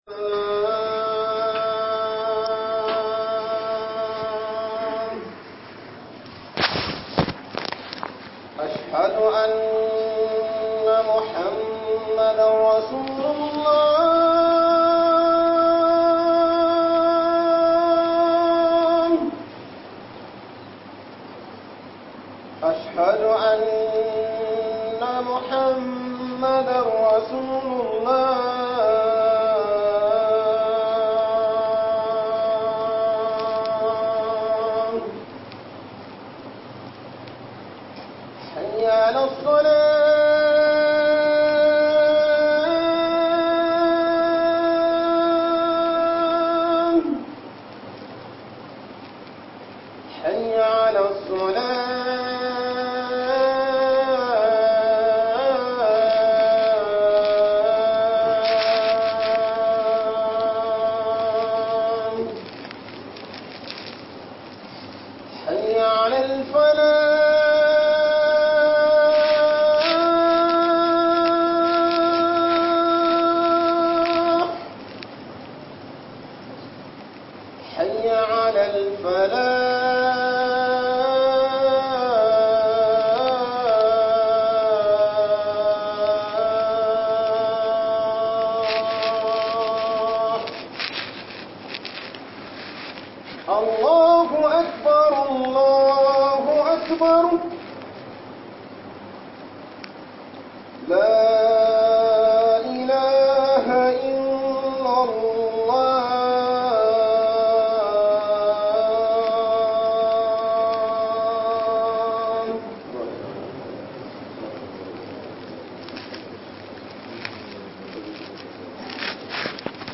Huduba Muharram - HUƊUBOBIN JUMA'A